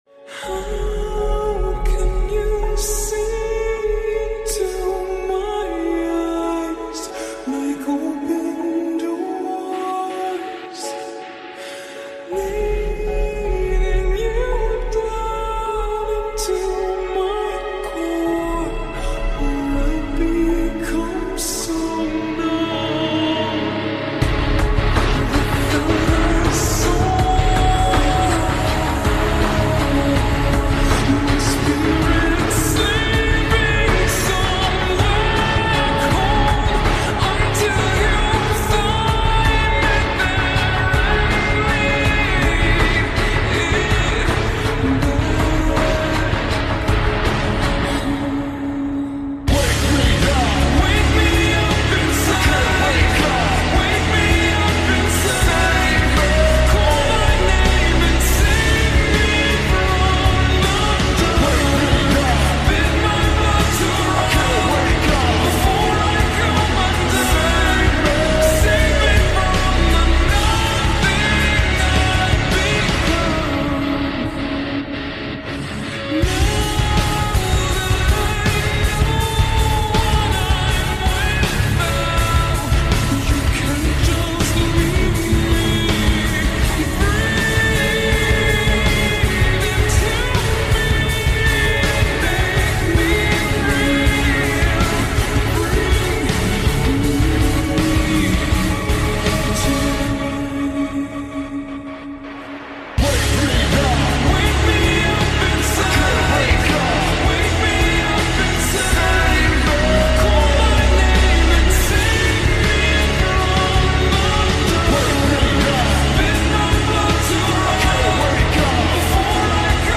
Orca Whale At The Ocean Sound Effects Free Download